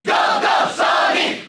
Sonic's cheer in the Japanese version of Brawl.
Sonic_Cheer_Japanese_SSBB.ogg